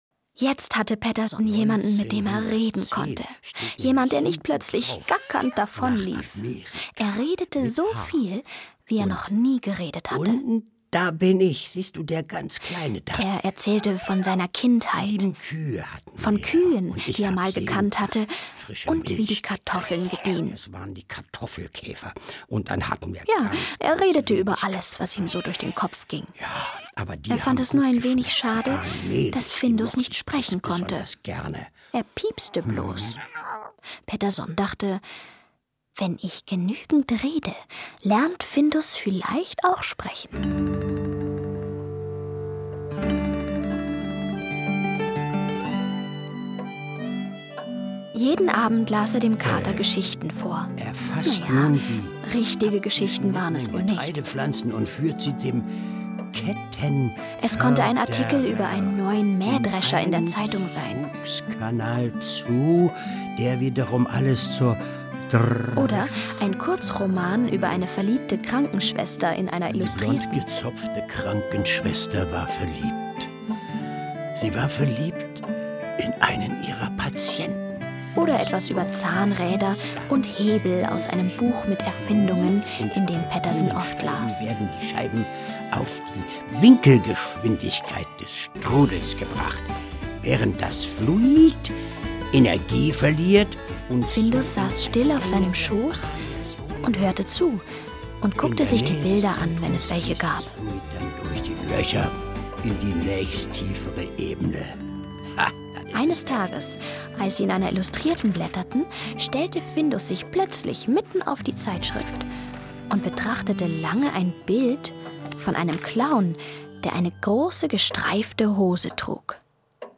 Ravensburger Wie Findus zu Pettersson kam ✔ tiptoi® Hörbuch ab 4 Jahren ✔ Jetzt online herunterladen!